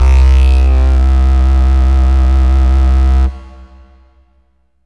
SYNTH LEADS-1 0006.wav